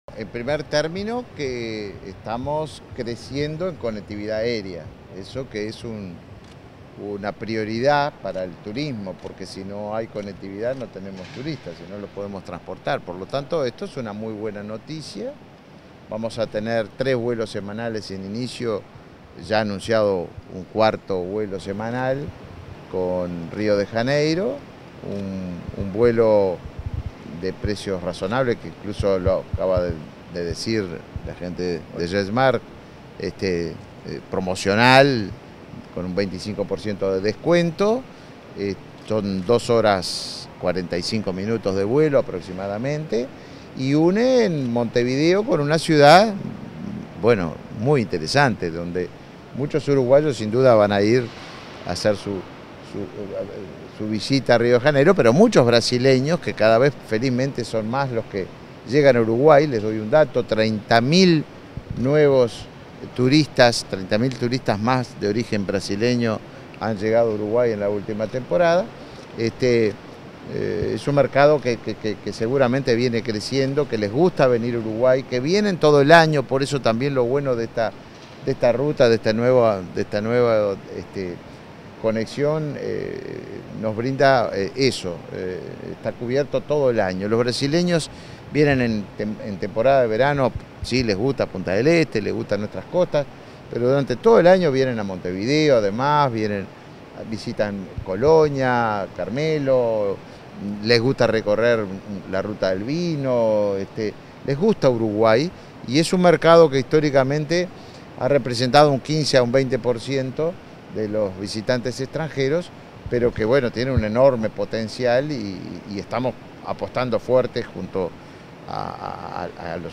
Declaraciones del ministro de Turismo, Tabaré Viera
Declaraciones del ministro de Turismo, Tabaré Viera 28/03/2023 Compartir Facebook X Copiar enlace WhatsApp LinkedIn Tras presenciar el arribo del vuelo inaugural entre Montevideo y Río de Janeiro de la empresa JetSmart, este 28 de marzo, el ministro de Turismo, Tabaré Viera, realizó declaraciones a la prensa.